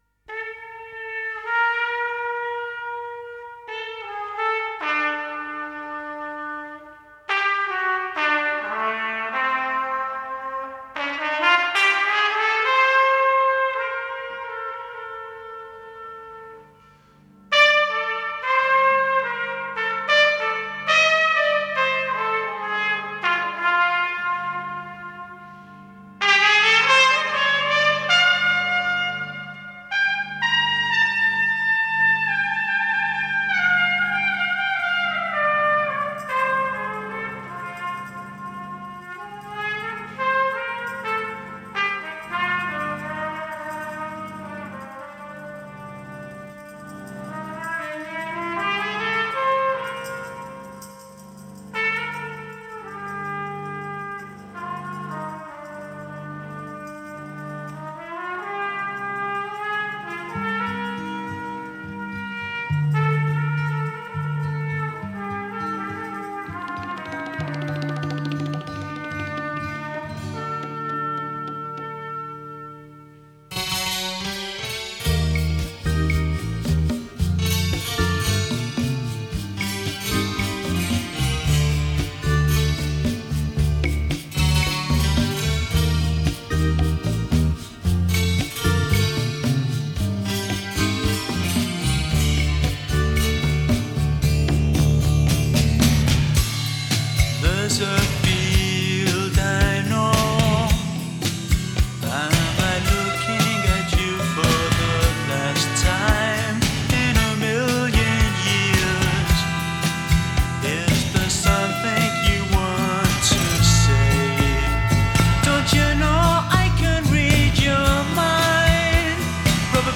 Genre: Indie, Lo-Fi, New Wave